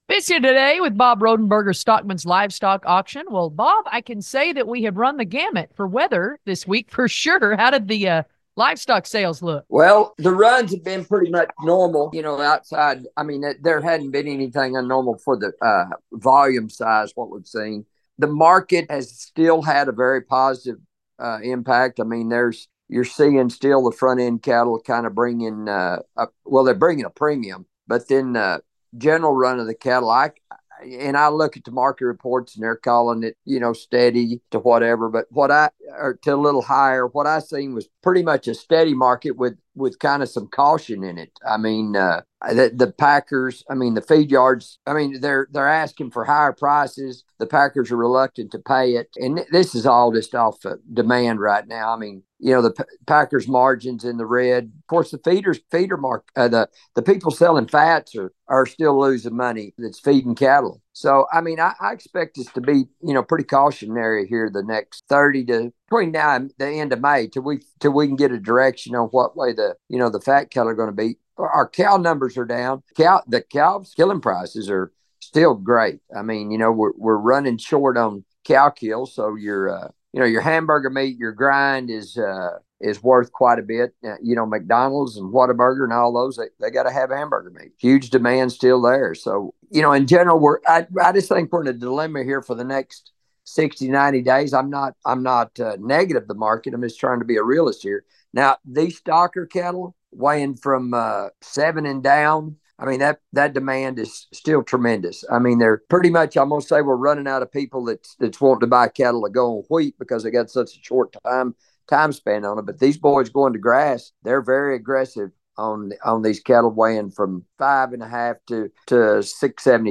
Here's our regular feature that is a part of the Monday Daily Email- market commentary